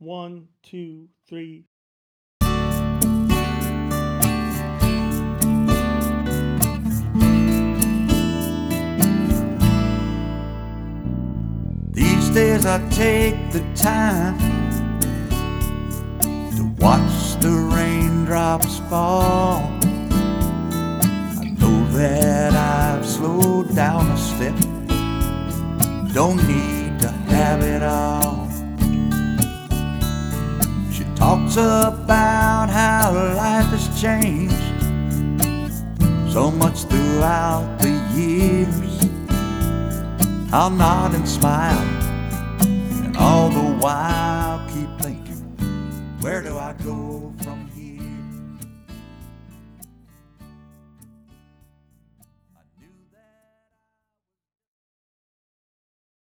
Here are some tracks from the studio that we hope you enjoy.